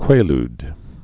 (kwāld)